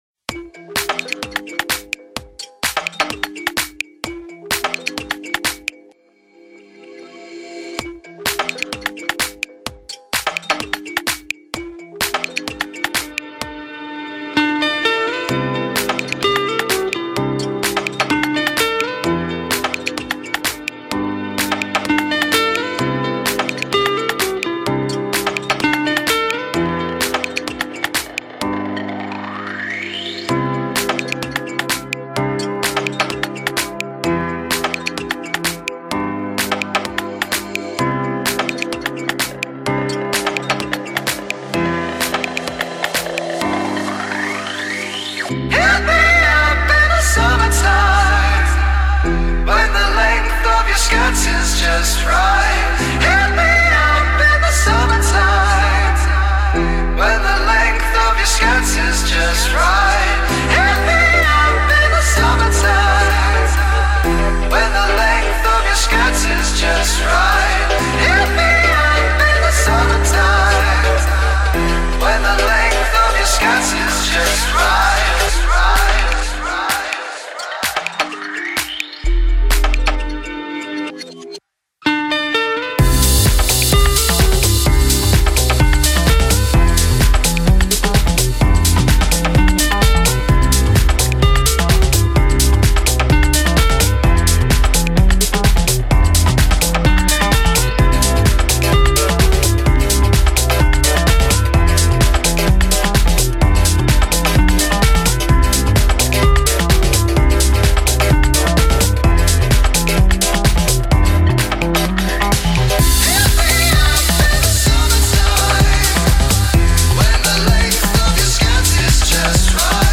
Mixed for broadcast